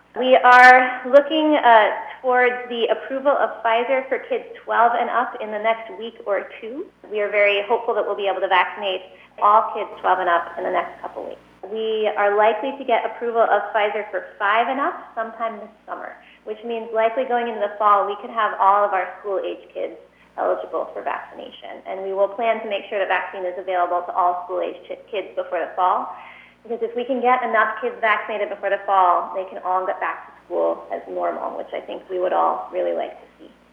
PORT ANGELES – Friday morning’s Covid briefing focused largely on the pandemic’s recent effect on local kids, and we got some new information about when they may be able to start vaccinating children under 16.
Dr. Berry also talked about the latest news on vaccines for children and when that might happen.